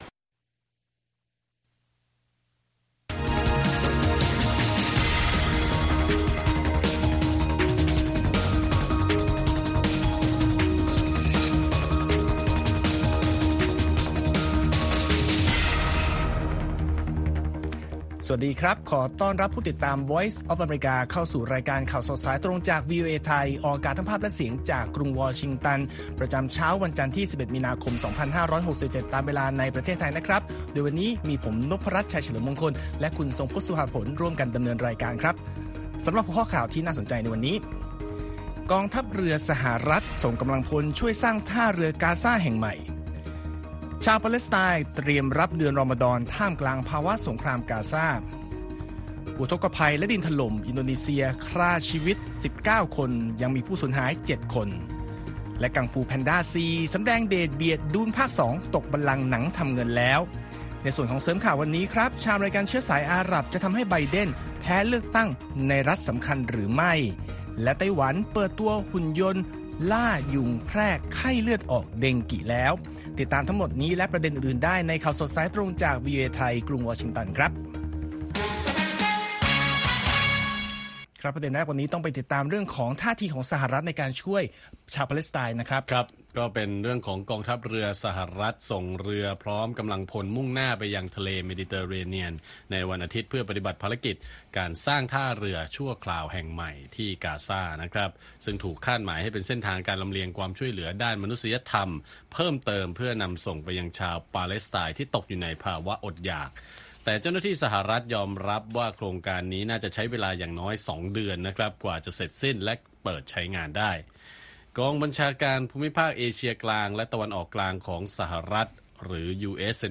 ข่าวสดสายตรงจากวีโอเอไทย จันทร์ ที่ 11 มี.ค. 67